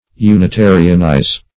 unitarianize - definition of unitarianize - synonyms, pronunciation, spelling from Free Dictionary
unitarianize.mp3